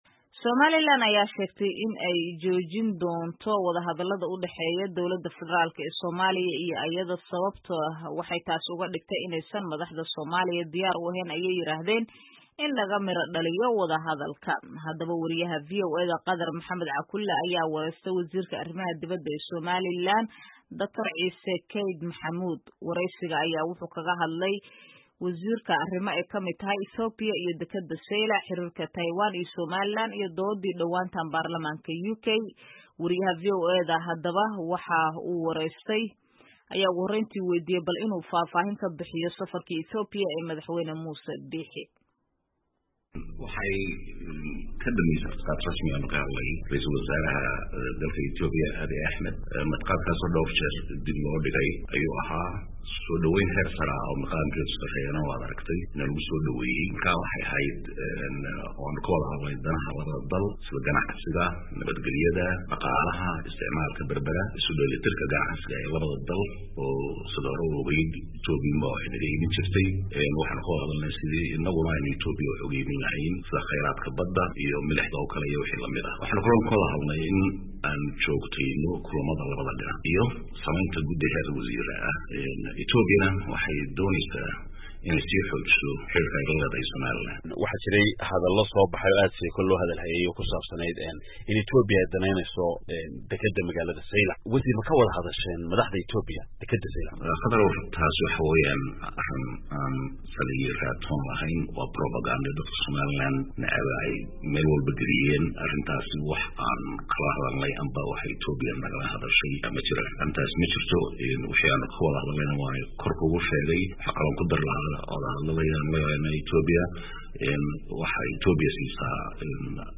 Wasiirka Arrimaha Dibedda Somaliland Dr. Ciise Kayd Maxamud ayaa wareysi uu siiyey VOA kaga hadlay arrimo ay ka mid yihiin Ethiopia, dekedda Zaylac, Xiriirka Taiwan iyo Somaliland iyo dooddii dhawaan uu Baarlamanka UK ka yeeshay aqoonsiga Somaliland.